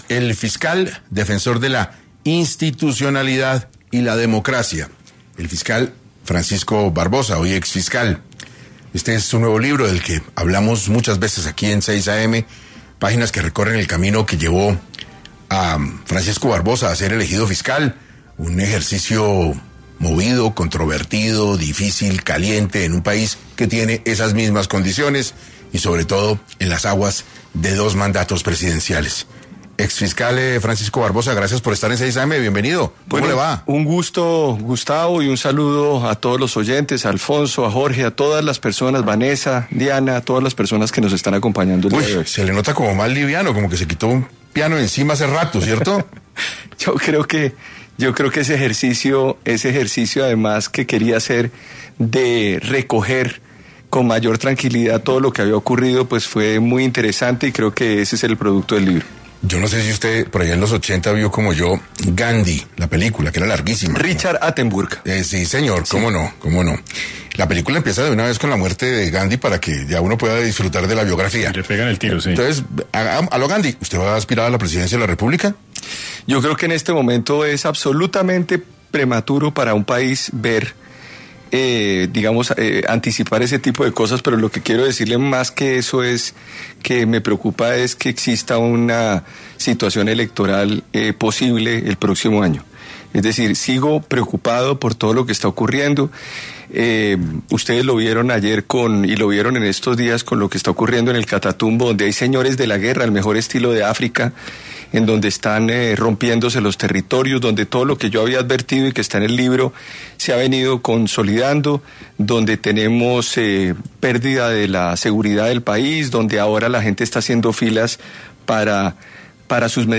Francisco Barbosa, exfiscal, estuvo en 6AM para abordar las temáticas de su libro ‘El Fiscal’.